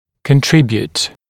[kən’trɪbjuːt][кэн’трибйут]способствовать, вносить вклад